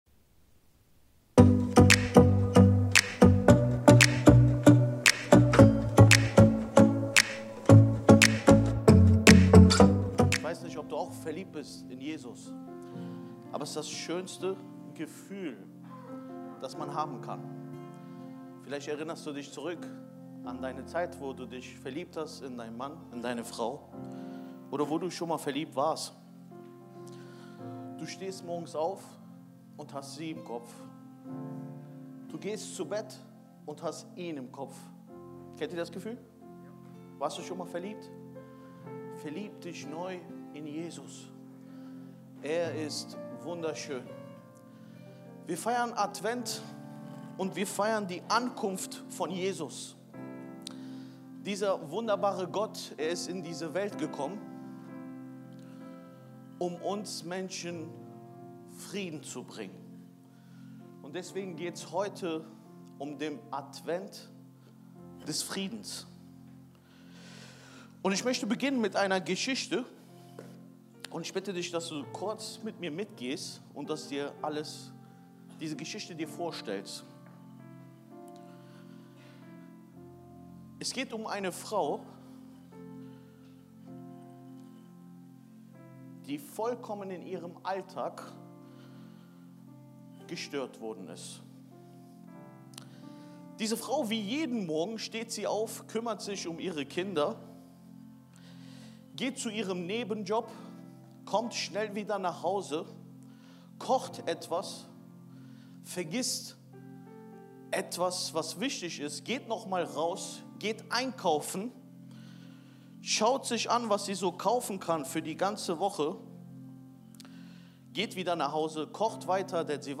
Video und MP3 Predigten
Kategorie: Sonntaggottesdienst Predigtserie: Advent - eine Ankunft, die verwandelt